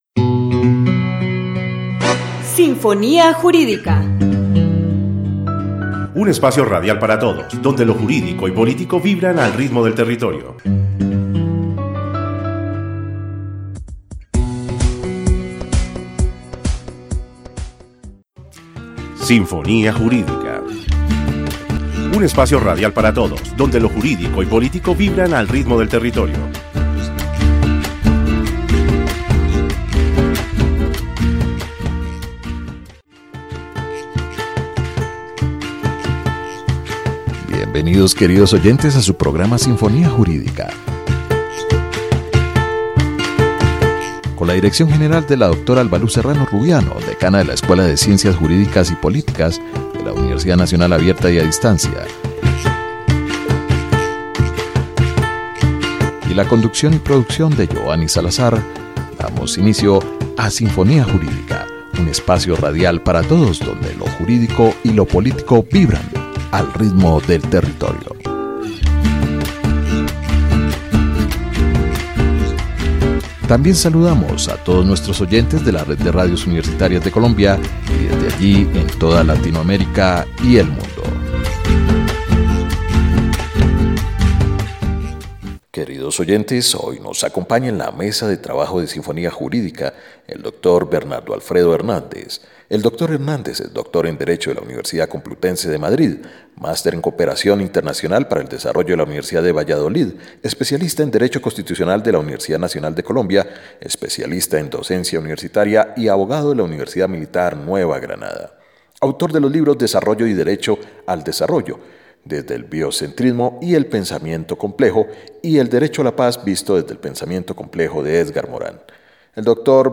Sinfonía Jurídica # 11- Complejidad Y Derecho Entrevista